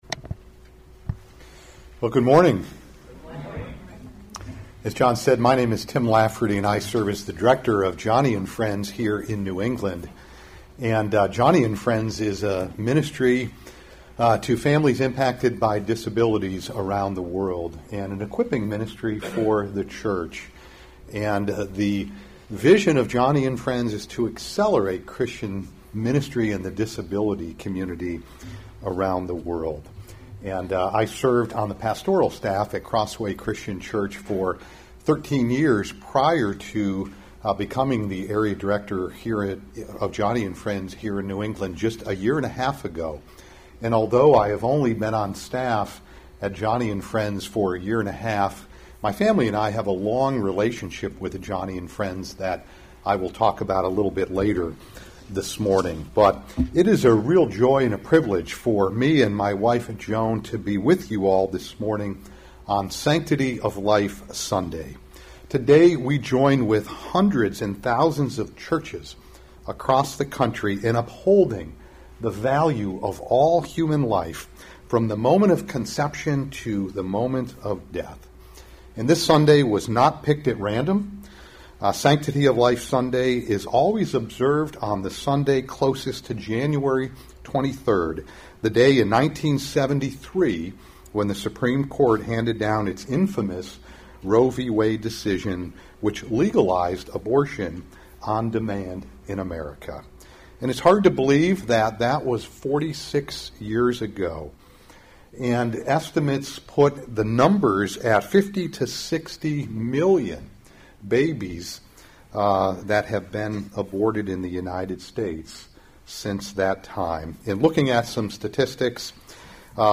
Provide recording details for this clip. Weekly Sunday Service